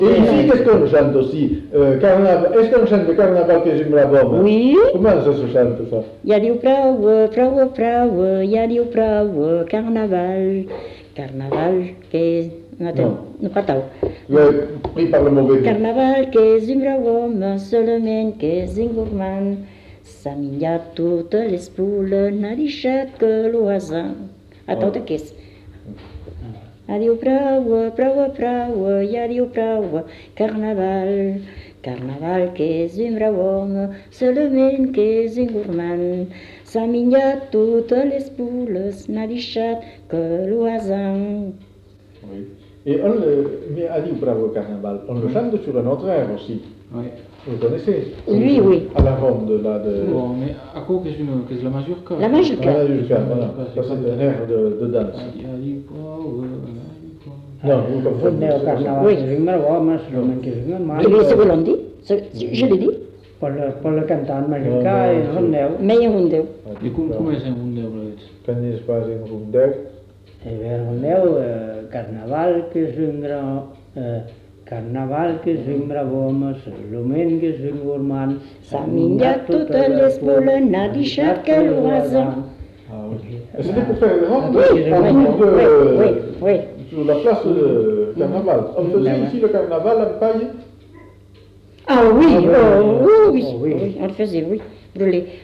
Lieu : Cazalis
Genre : chant
Effectif : 2
Type de voix : voix de femme ; voix d'homme
Production du son : chanté
Danse : rondeau ; mazurka
Classification : chanson de carnaval
Chanté sur deux airs différents.